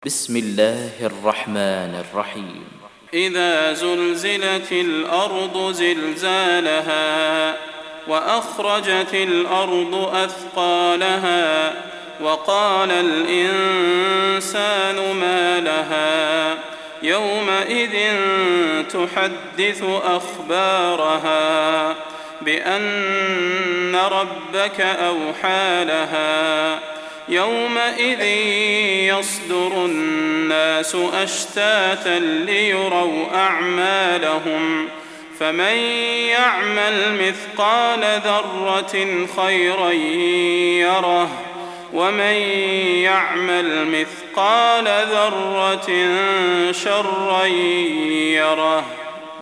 Audio Quran Tarteel Recitation
Surah Repeating تكرار السورة Download Surah حمّل السورة Reciting Murattalah Audio for 99. Surah Az-Zalzalah سورة الزلزلة N.B *Surah Includes Al-Basmalah Reciters Sequents تتابع التلاوات Reciters Repeats تكرار التلاوات